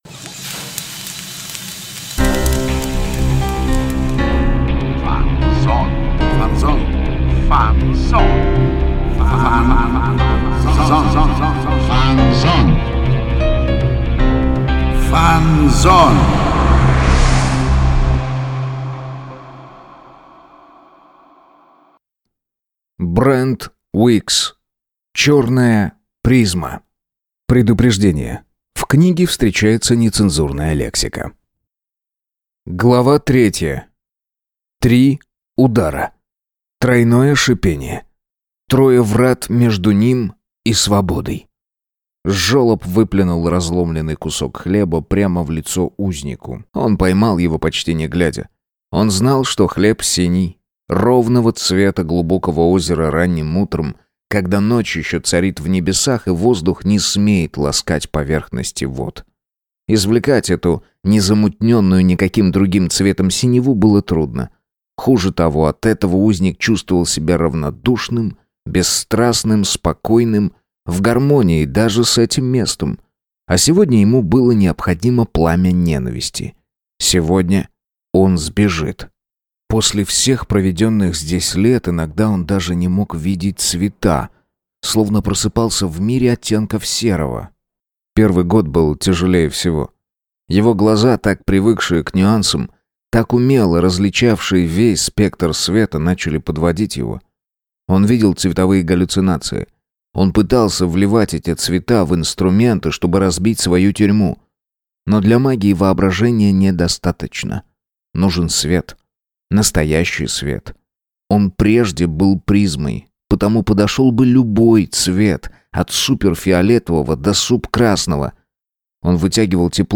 Аудиокнига Черная Призма | Библиотека аудиокниг
Прослушать и бесплатно скачать фрагмент аудиокниги